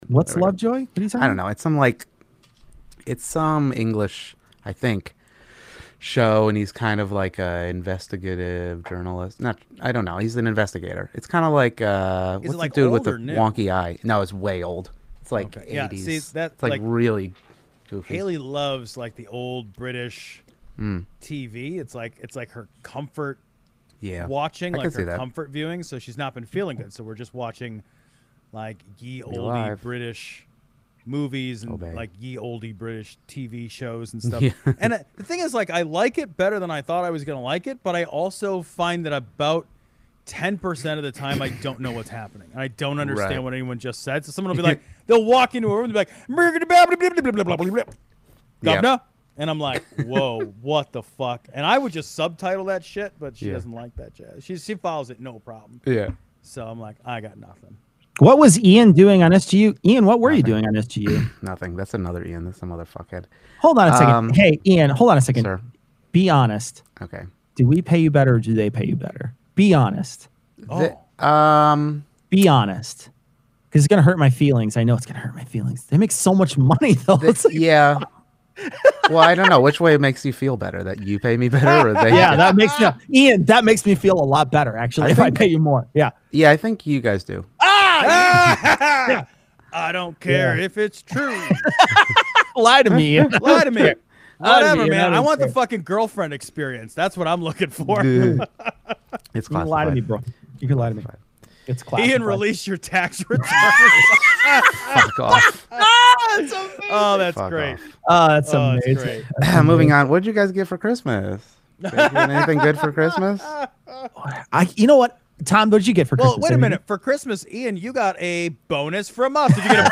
livestream audio